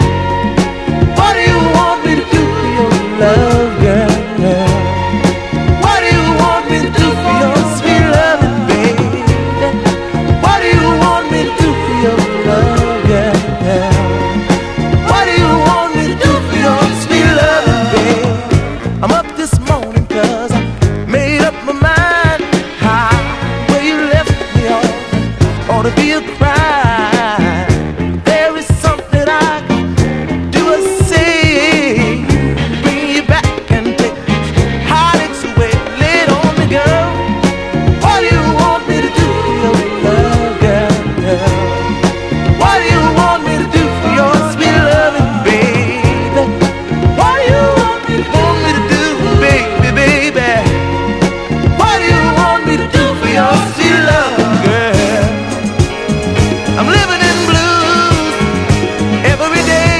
DISCO / DISCO FUNK
マイナーな存在ながら侮れないクオリティのディスコ・ファンク！